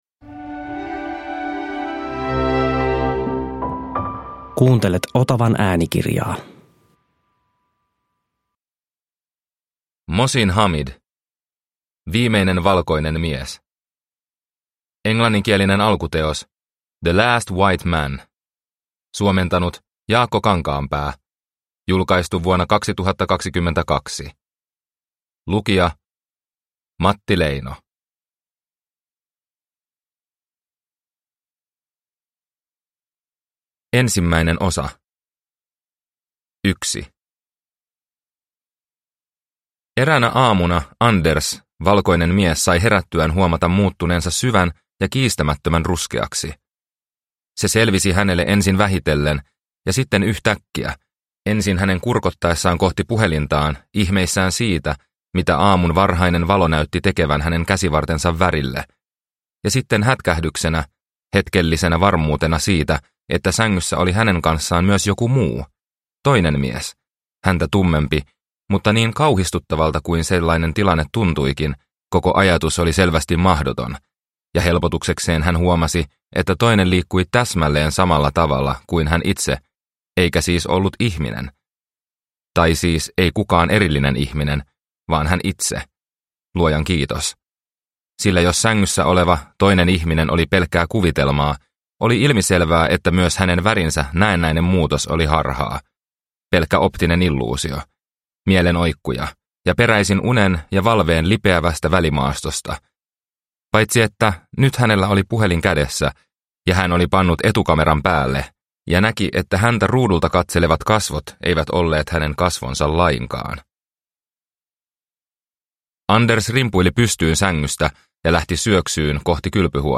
Viimeinen valkoinen mies – Ljudbok – Laddas ner